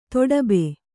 ♪ toḍabe